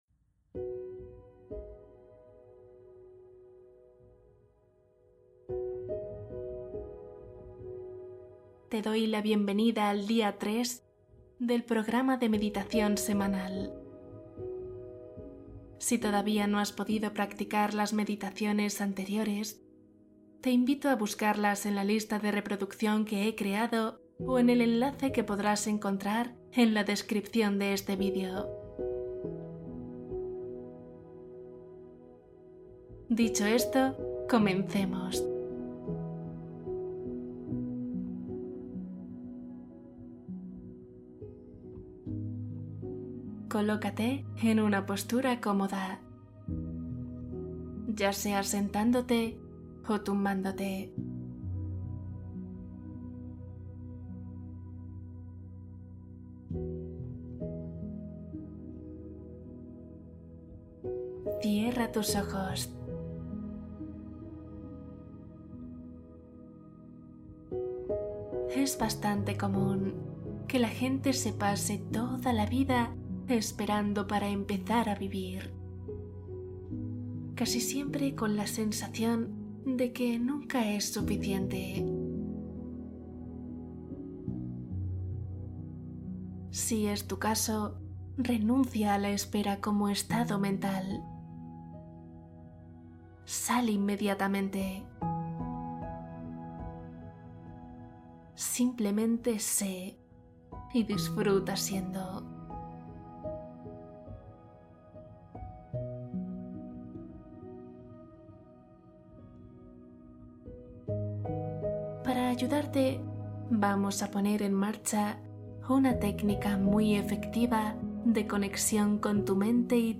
Día 3: conectar cuerpo y mente — meditación de integración profunda